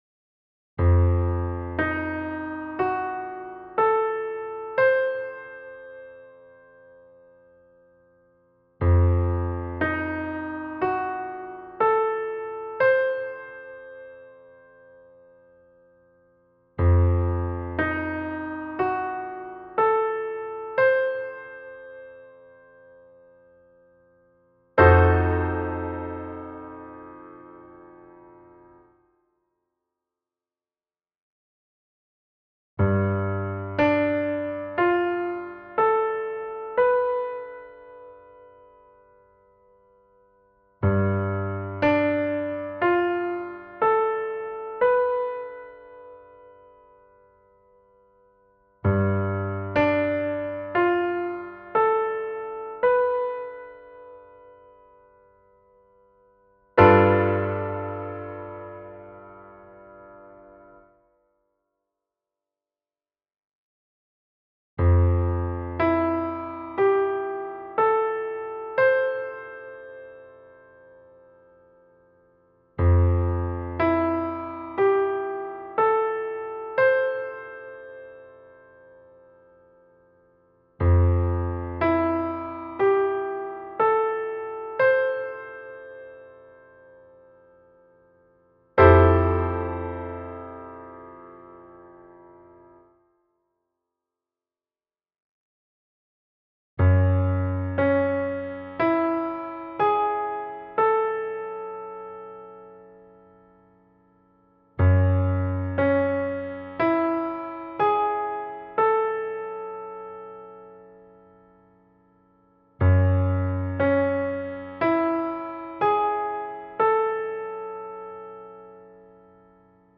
Riconoscimento e Identificazione voicings in forma di arpeggio e accordo